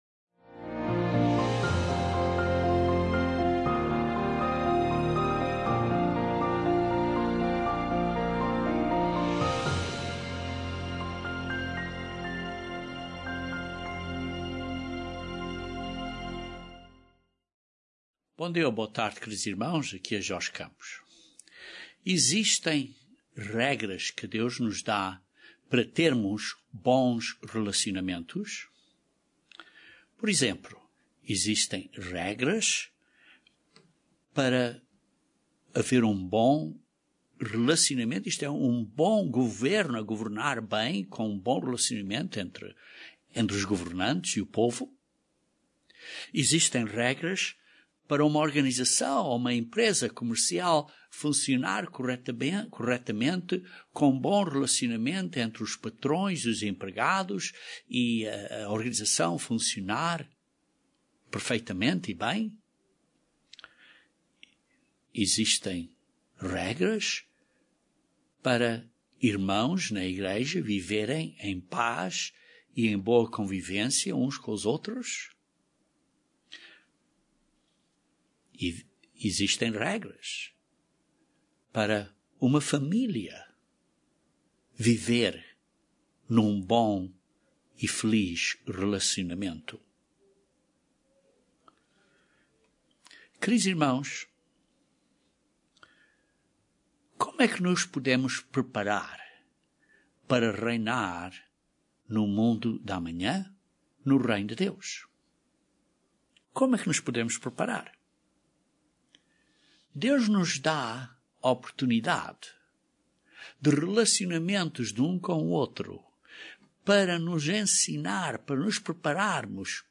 O matrimónio é um relacionamento pelo qual aprendemos princípios importantes para nos prepararmos para reinar e servir no mundo de amanhã debaixo de Cristo. Este sermão descreve três pontos simples mas importantes para crescermos no caráter santo e sagrado de Deus.